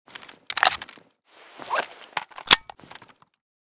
mp7_reload.ogg